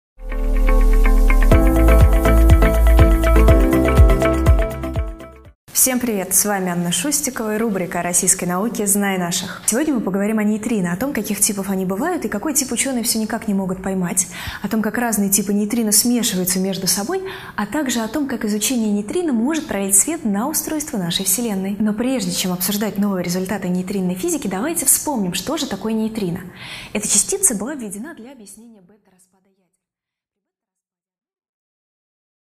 Aудиокнига О нейтрино и антинейтрино Автор Анна Шустикова.